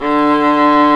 Index of /90_sSampleCDs/Roland L-CD702/VOL-1/STR_Viola Solo/STR_Vla1 % marc